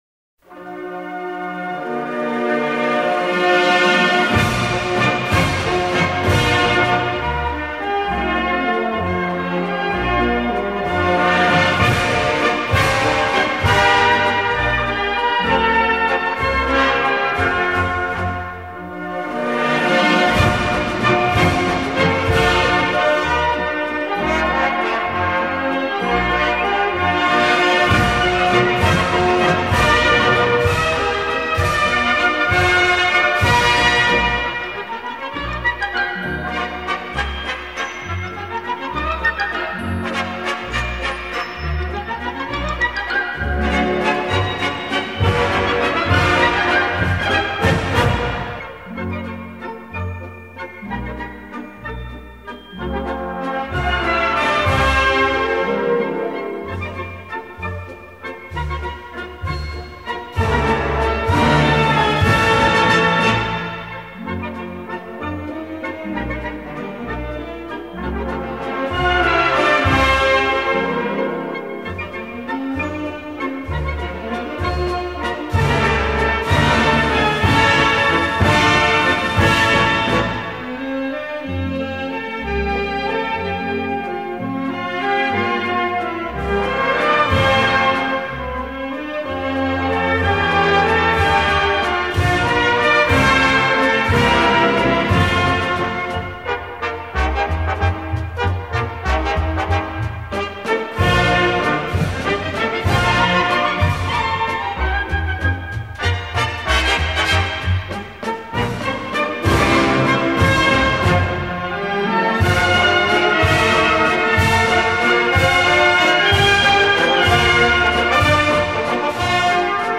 Gattung: Konzertwalzer
Besetzung: Blasorchester